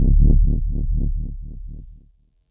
Low_Rumble02.wav